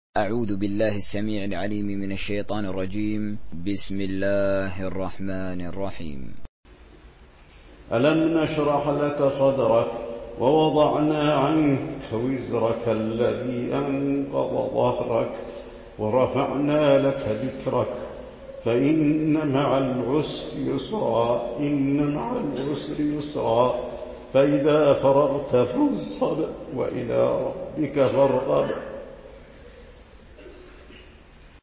روایت حفص از عاصم
تراويح